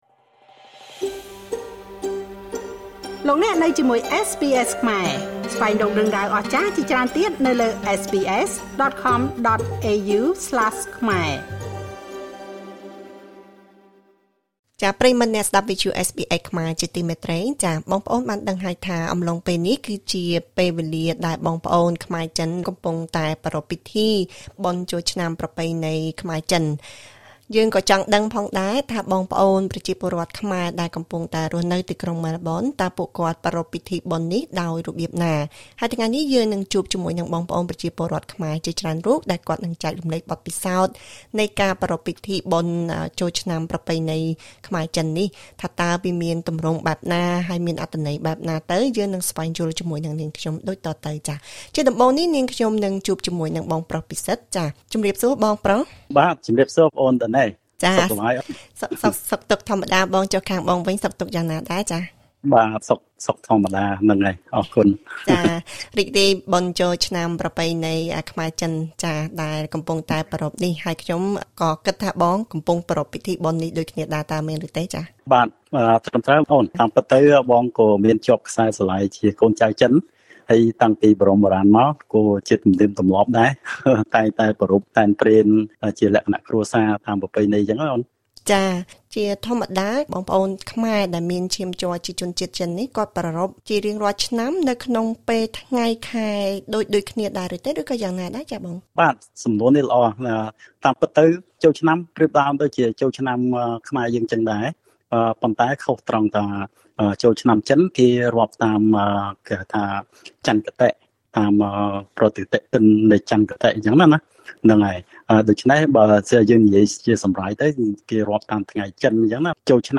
សូមស្តាប់បទសម្ភាសន៍ជាមួយពលរដ្ឋខ្មែរអូស្រ្តាលី ដែលរៀបរាប់អំពីពិសោធន៍របស់ពួកគេក្នុងការប្រារព្ធពិធីបុណ្យចួលឆ្នាំចិននេះ។